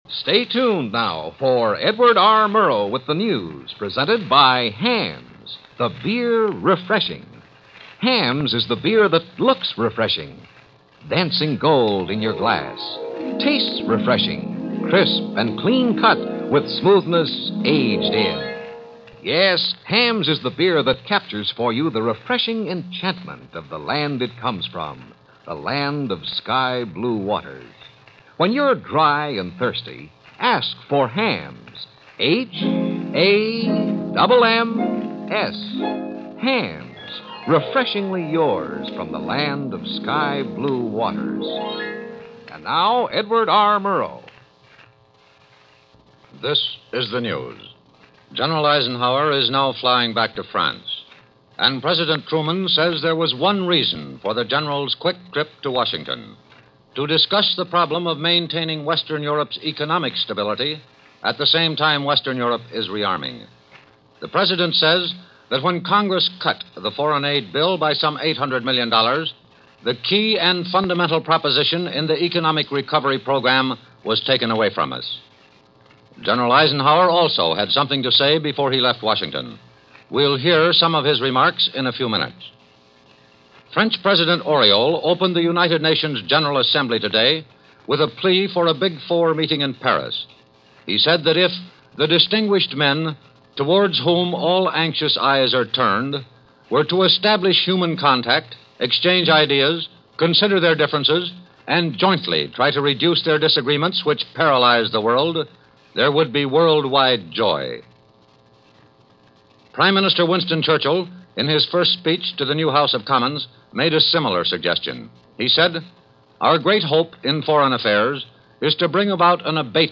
News-November-6-1951.mp3